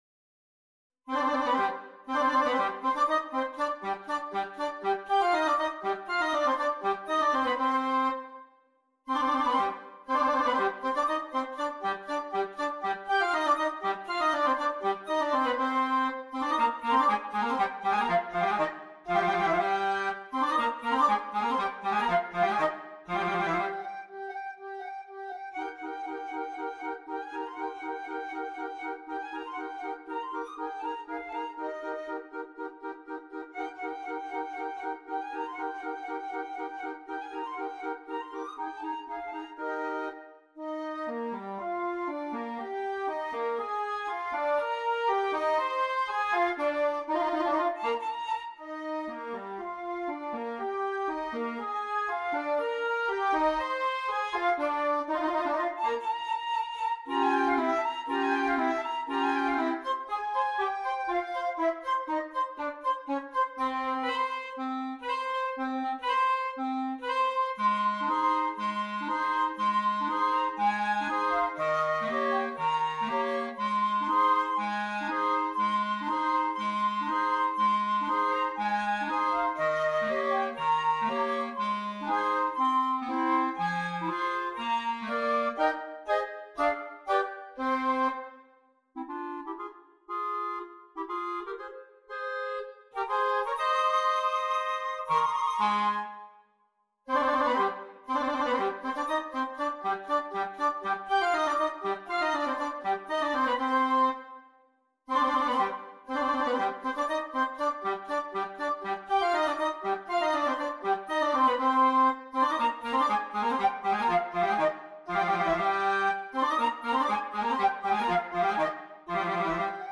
2 Flutes, 2 Clarinets
rousing
This is a flashy piece and everyone gets a good part.